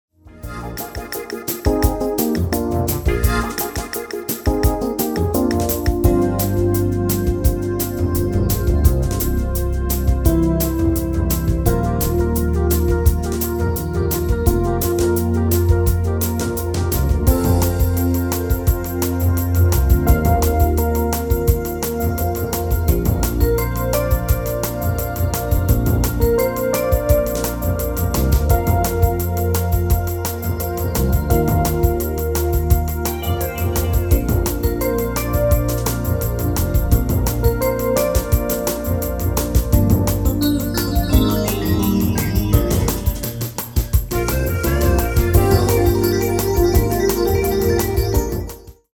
Genre: Evergreens & oldies
Toonsoort: Bb
- Vocal harmony tracks
Demo's zijn eigen opnames van onze digitale arrangementen.